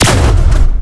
fire_capship_forward_gun.wav